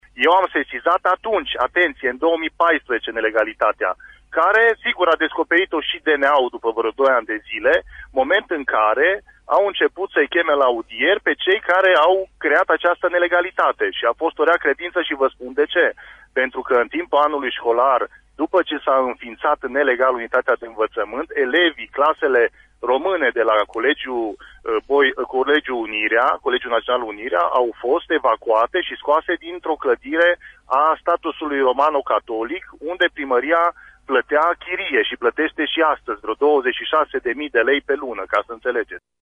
În privința situației de la Tg. Mureș, deputatul PMP de Mureș Marius Pașcan a declarat că legea a fost încălcată încă de la început și că a sesizat acest lucru autorităților, la acel moment: